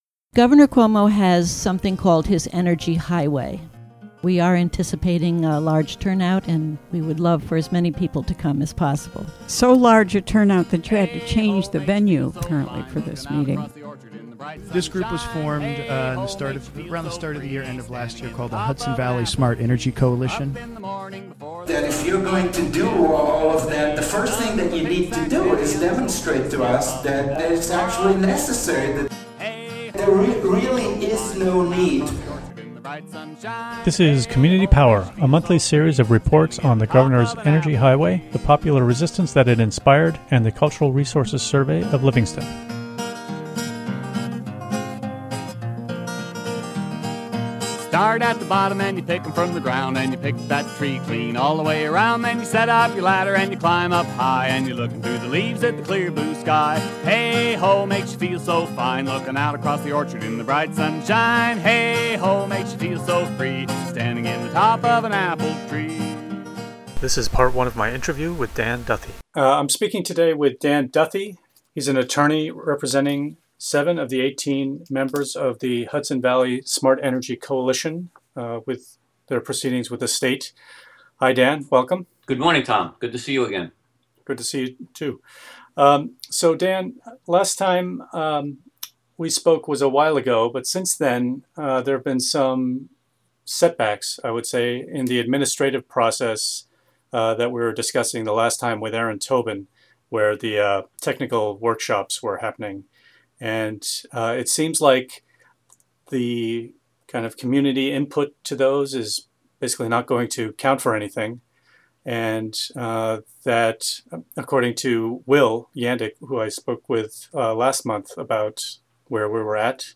The interview is part of "Community Power," a year-long series of conversations about the New York Energy Highway Blueprint, the local response to that initiative, and the Cultural Resources Survey of Livingston.